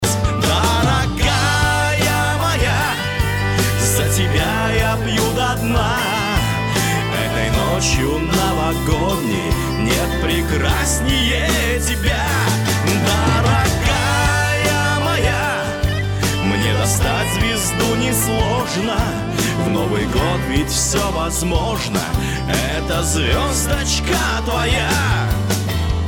• Качество: 256, Stereo
романтичные
русский шансон
праздничные